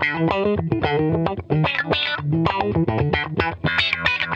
FUNK-E 3.wav